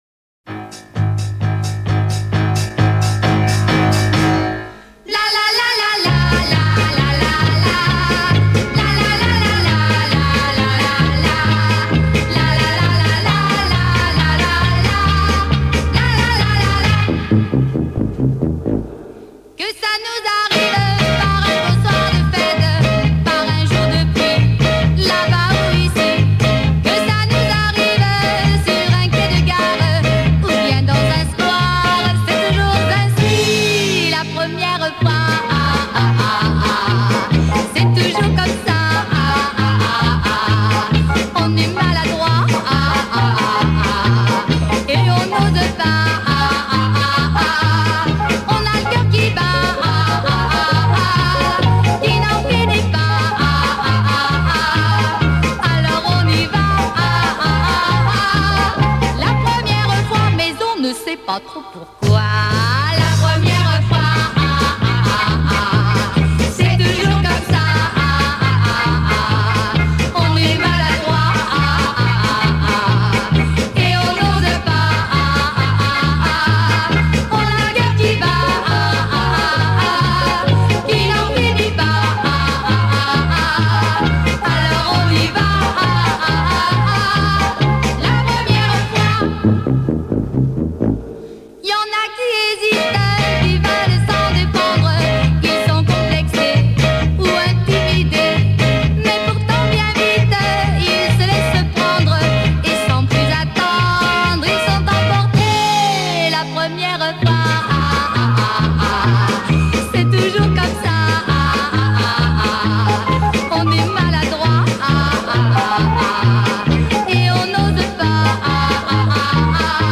Поют на французском